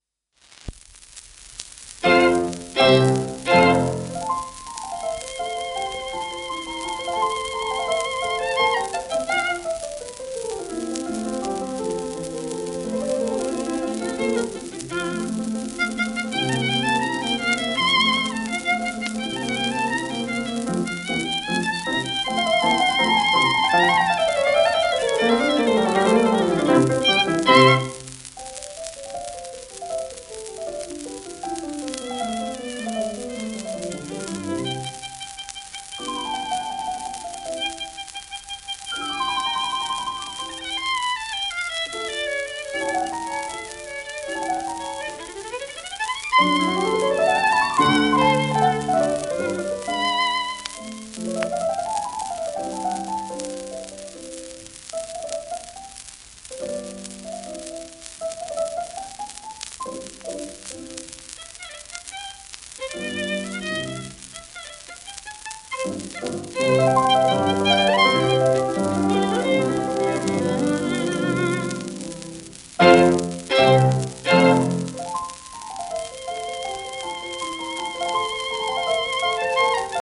ユーディ・メニューイン(Vn:1916-99)
w/ヘプシバ・メニューイン(p)
1938年、妹ヘプシバとの共演録音
HEPHZIBAH&YEHUDIMENUHIN_mozart_K376.m4a